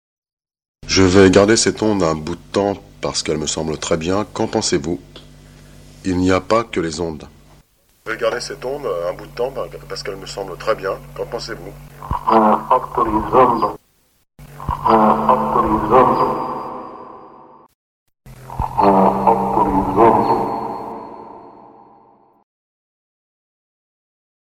J’utilise des ondes Allemandes ou slaves (non latines) stables, non brouillées.
Exemples de Transcommunication instrumentale (T.C.I.)